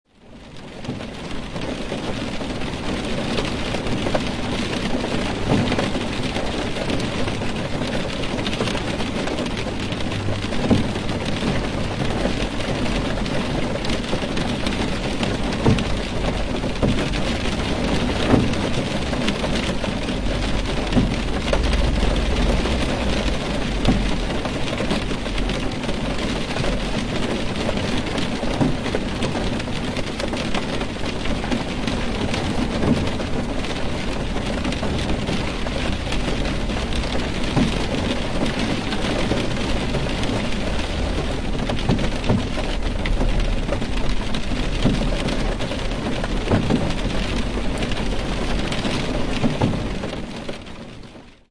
Здесь собраны аудиозаписи, которые раскрывают весь потенциал стереозвука — от успокаивающих мелодий до динамичных эффектов.
Дождь барабанит по крыше машины